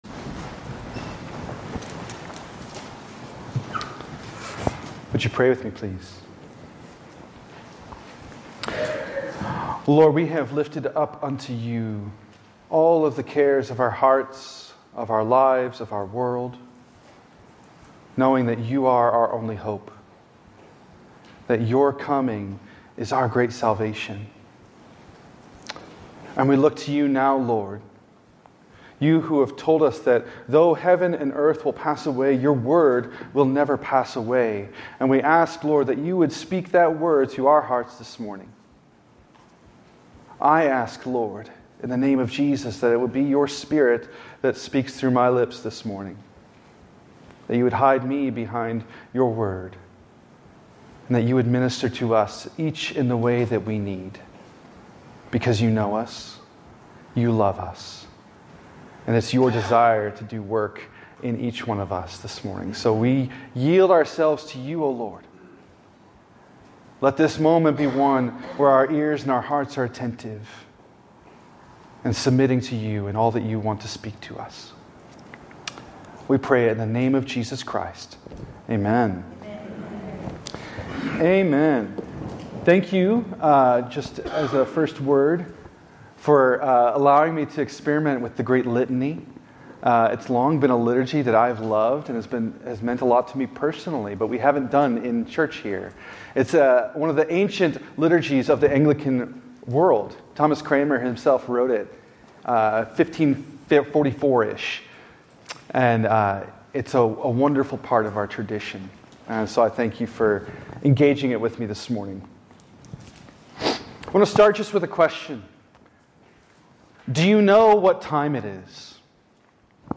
In this sermon from the first Sunday of Advent, 2025